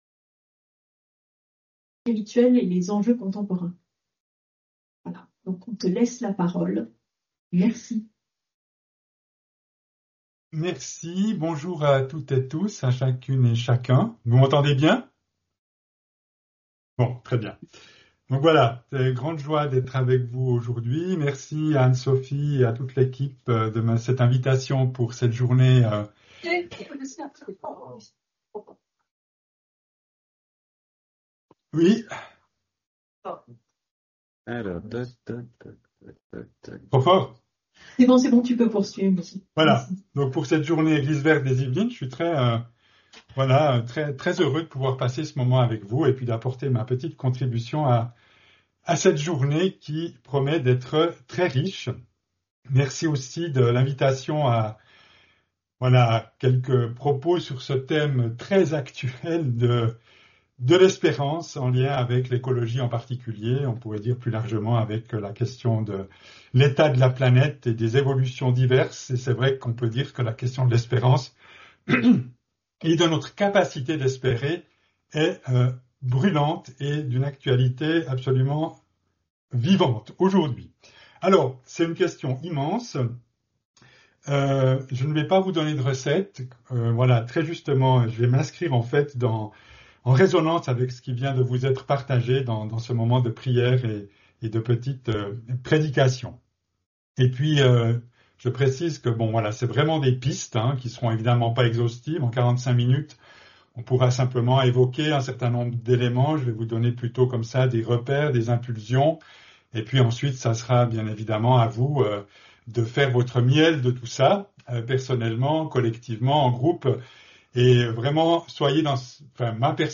lors de la journée des communautés « Eglise verte » des Yvelines
conférence